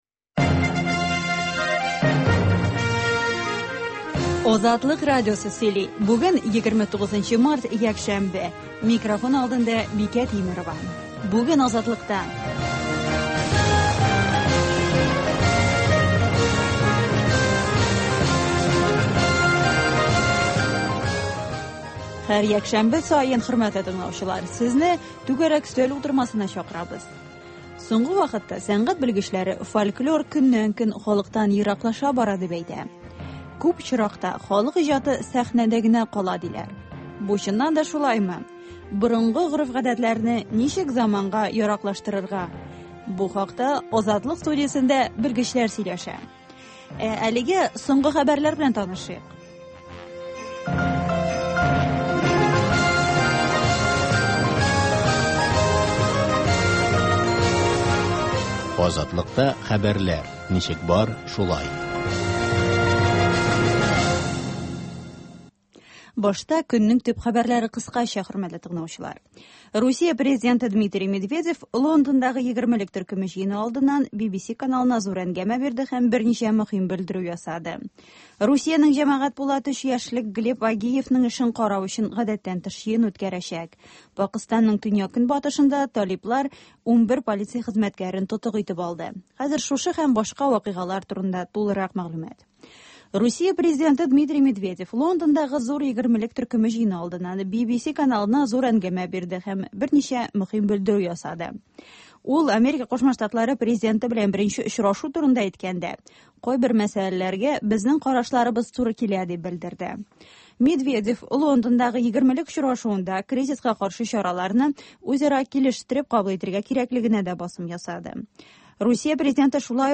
Азатлык узган атнага күз сала - соңгы хәбәрләр - түгәрәк өстәл сөйләшүе